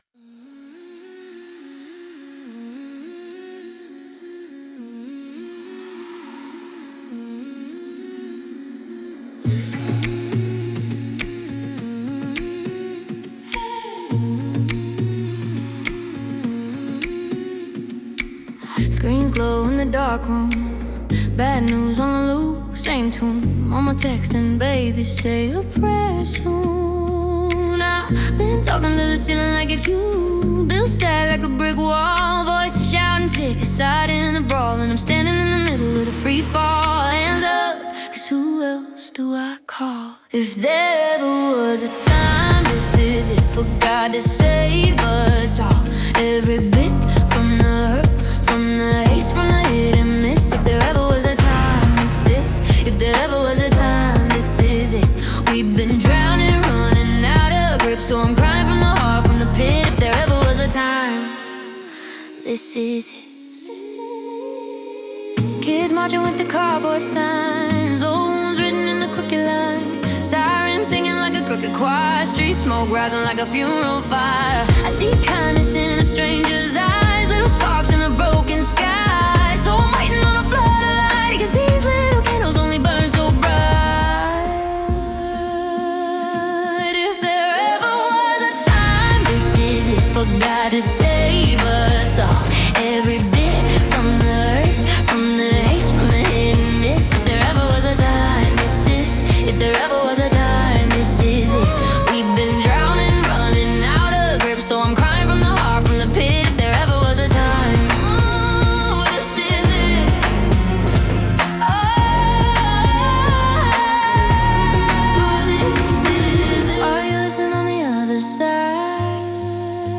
Genre: Gospel / Spiritual / Chant